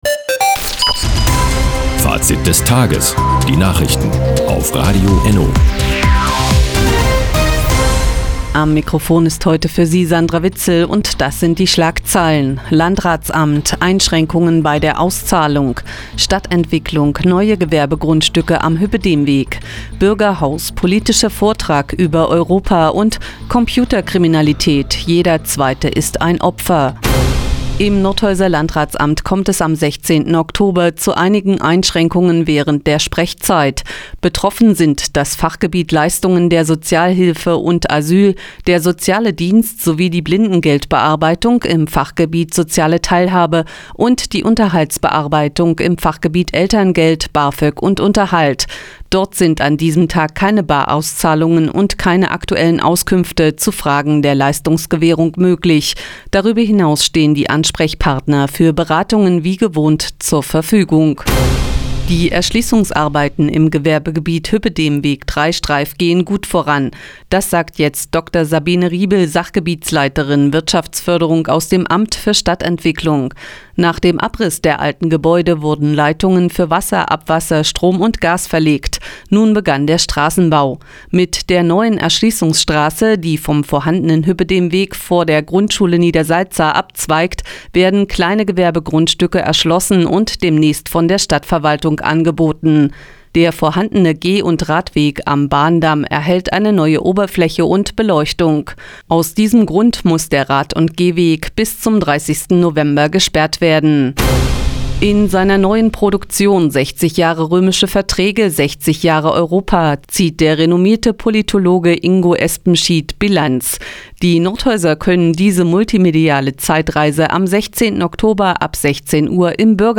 Di, 15:58 Uhr 10.10.2017 Neues von Radio ENNO Fazit des Tages Enno (Foto: Enno) Seit Jahren kooperieren die Nordthüringer Online-Zeitungen und das Nordhäuser Bürgerradio ENNO. Die tägliche Nachrichtensendung ist jetzt hier zu hören...